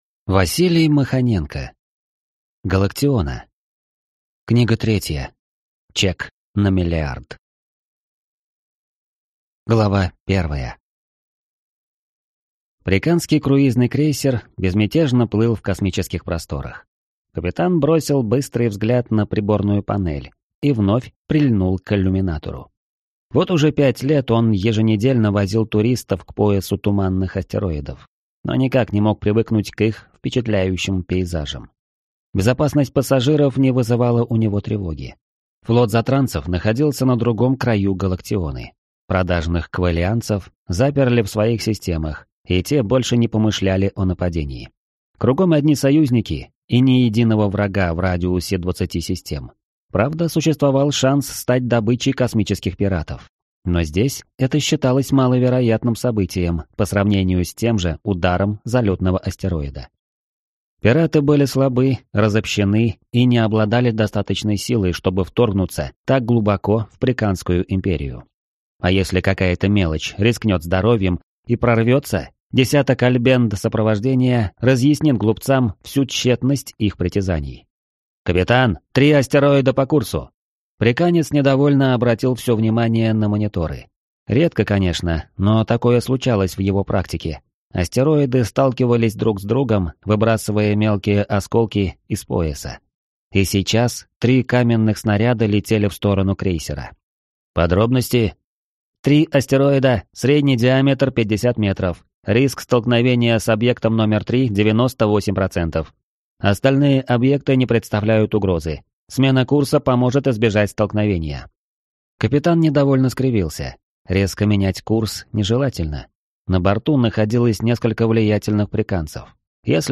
Аудиокнига Галактиона. Чек на миллиард | Библиотека аудиокниг